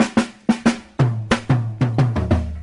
Chopped Fill 1.wav